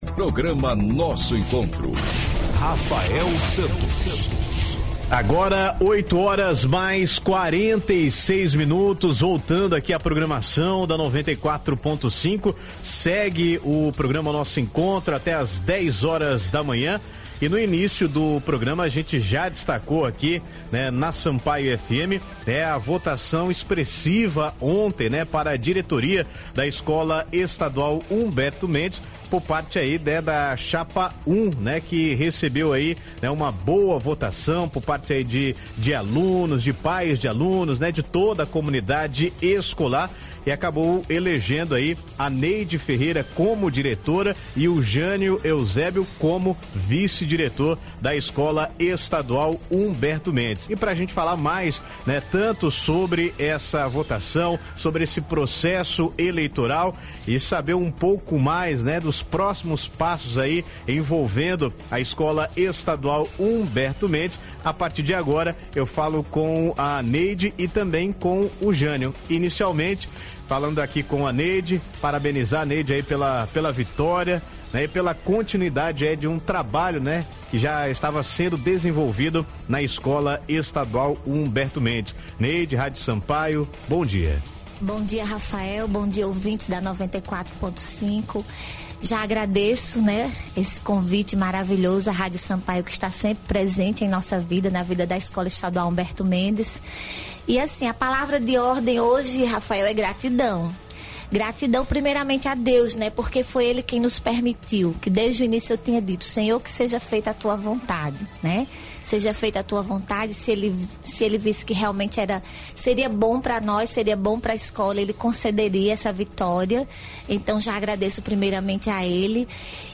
[Áudio e vídeos] Diretoria eleita da Escola Estadual Humberto Mendes concede entrevista à Rádio Sampaio - RÁDIO SAMPAIO
Entrevista-completa.mp3